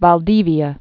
(väl-dēvē-ə, bäl-dēvyä)